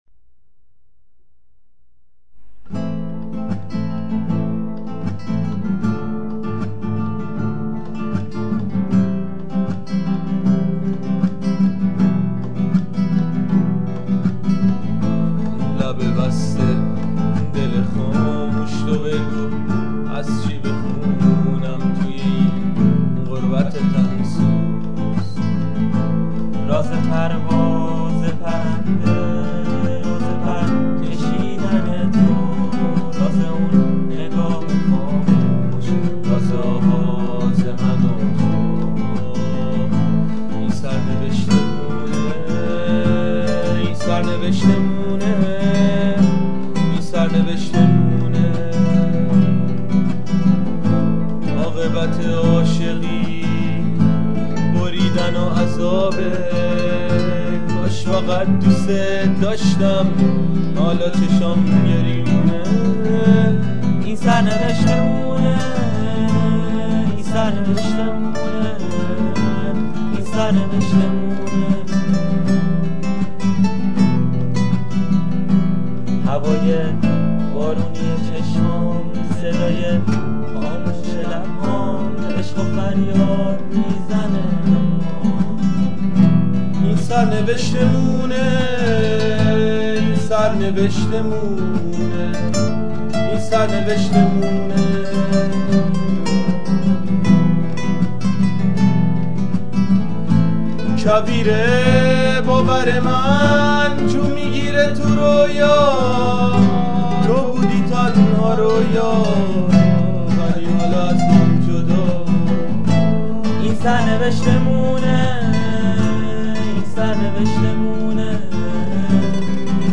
توی این آهنگ یه تیکه یکی تار میزنه که واقعاً قشنگه.